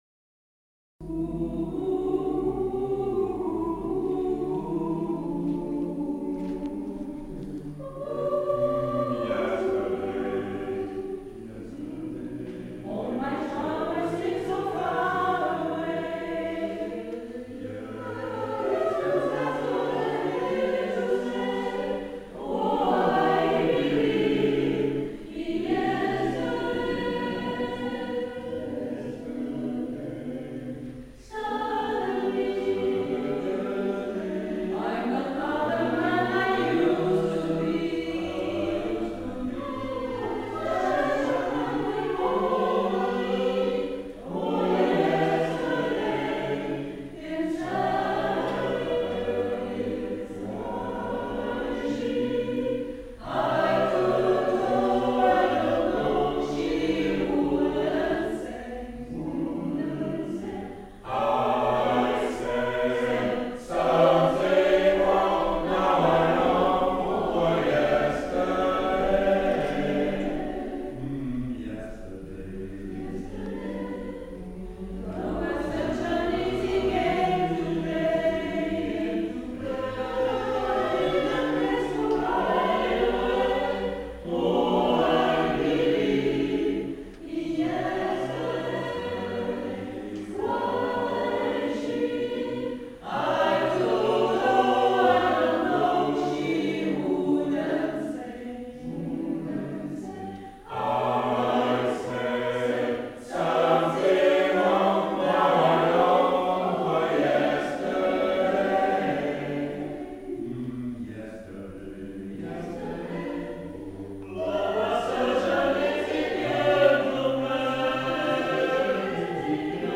Arrangement pour 4 voix mixtes